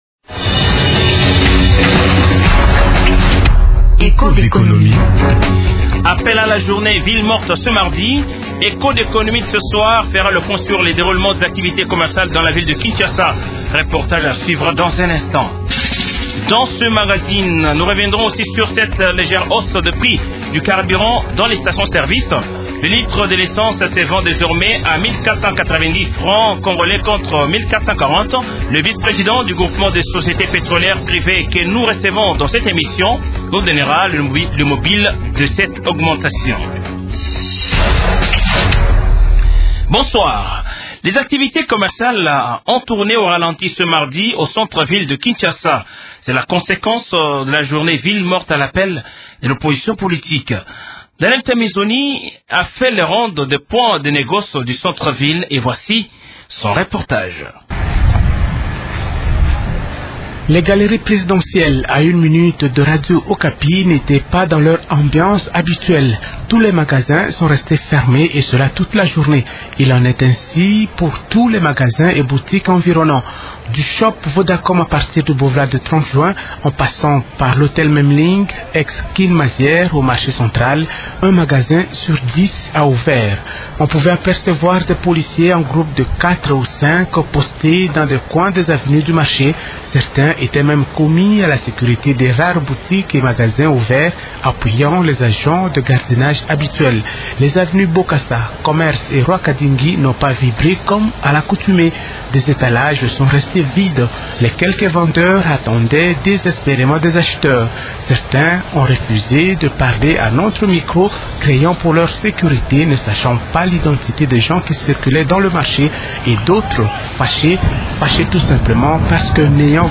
Cette information est traitée dans l’émission « Echos d’Economie » de ce jour.